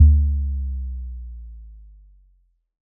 Bass Power Off 7.wav